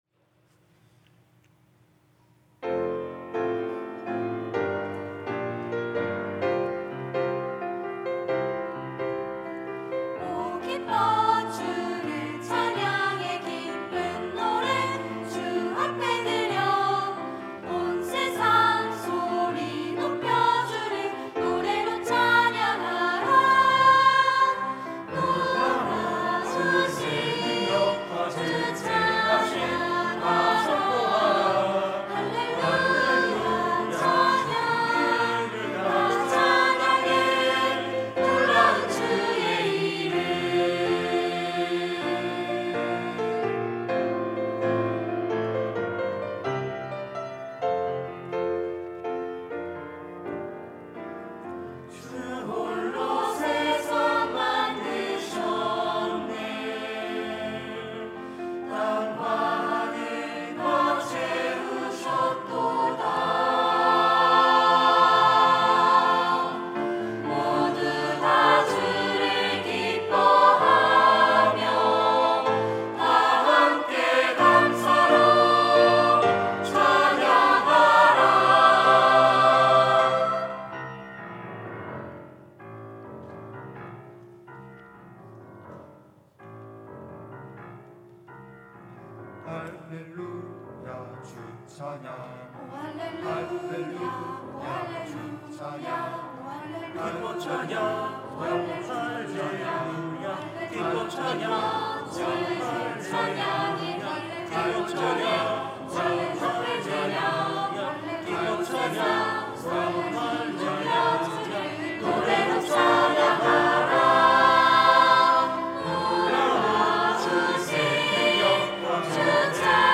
특송과 특주 - 기쁨으로 노래하라
청년부 카이노스 찬양대